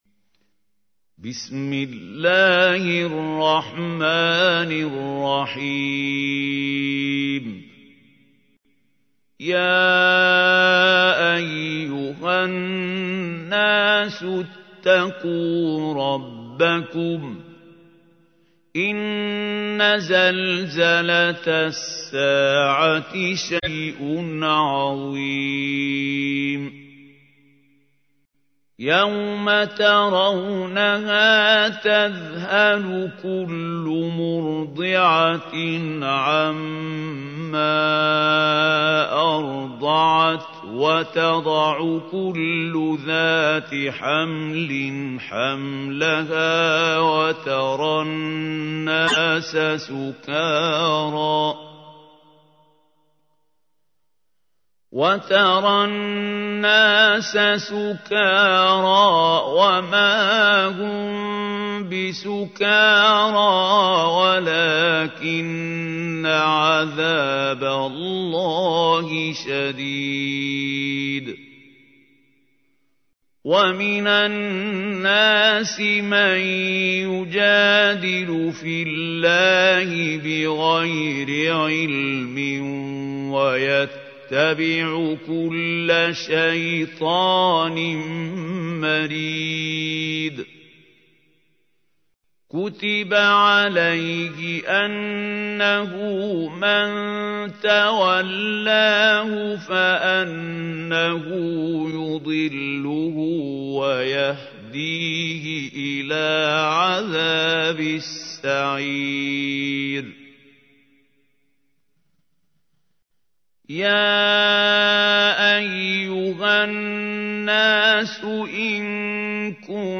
تحميل : 22. سورة الحج / القارئ محمود خليل الحصري / القرآن الكريم / موقع يا حسين